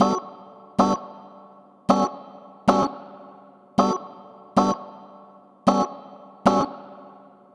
Swingerz 4 Organ-E.wav